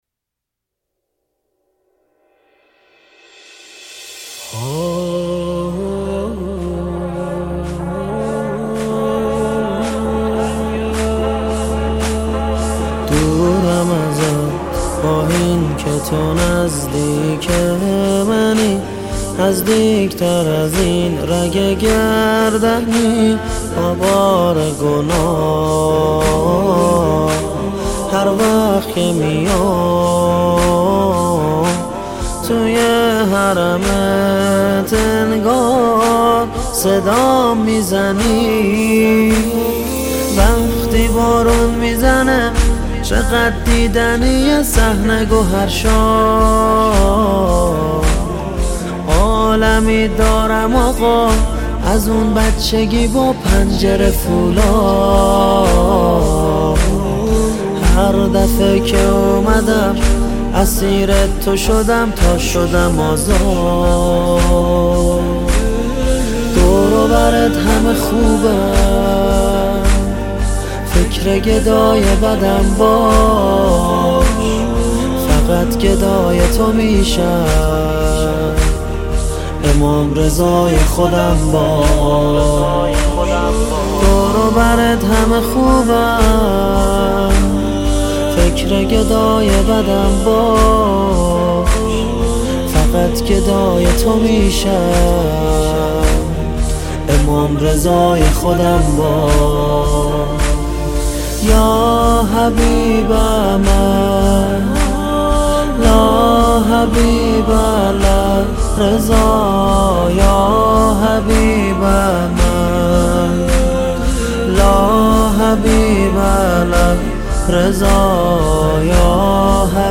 ویژه شهادت امام رضا (ع)
مداحی